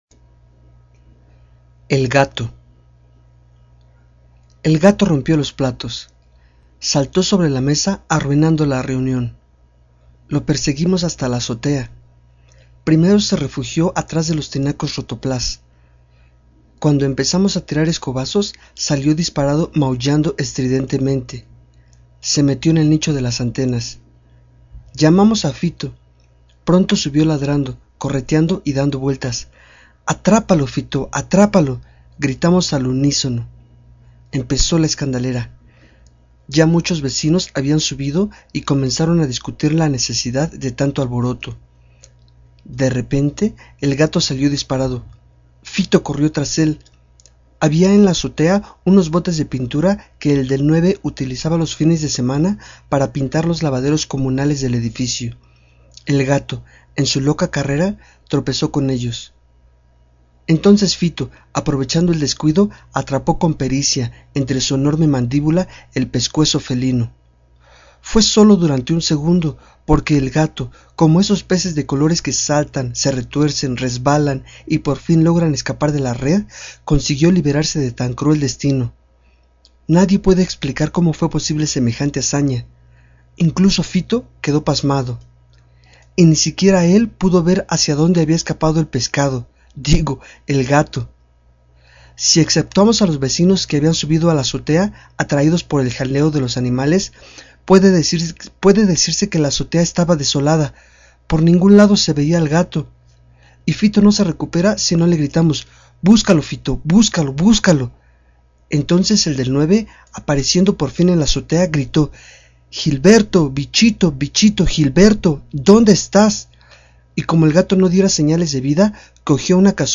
Cuenta cuentos
Leer historias y grabarlas con un micrófono. Contar cuentos. Podcast.